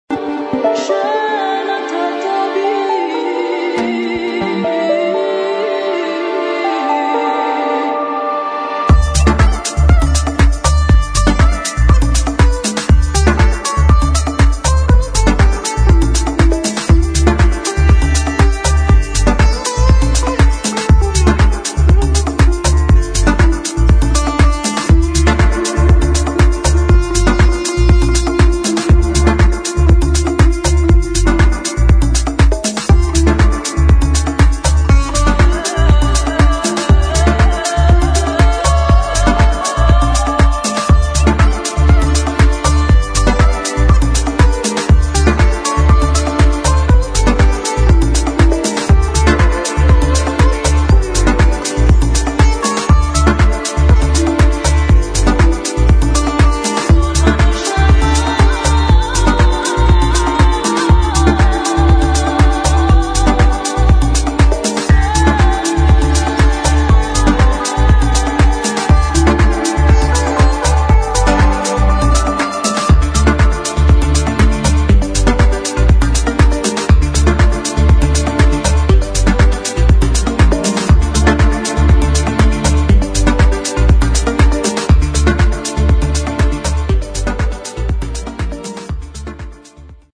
[ HOUSE / TECHNO ]
中東的な女性の声と弦楽器で構成されたピークタイム・アンセム